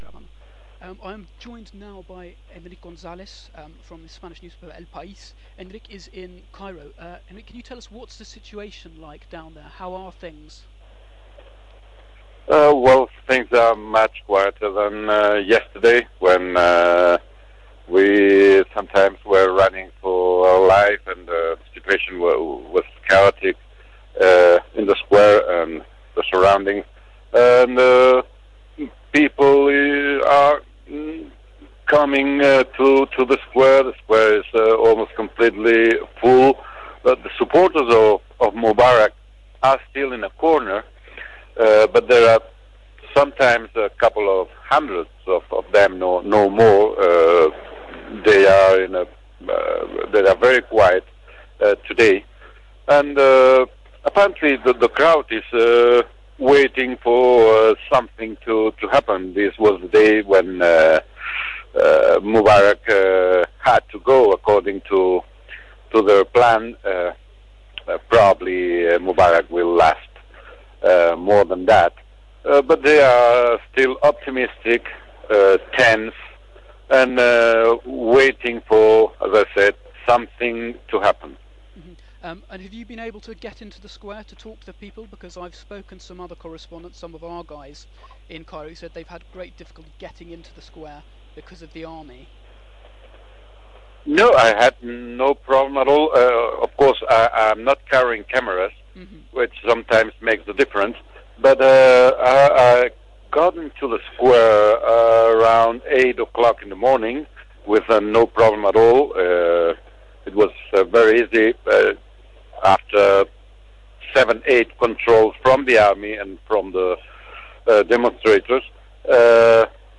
reoprts from Cairo